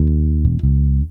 808s
Bass_08E.wav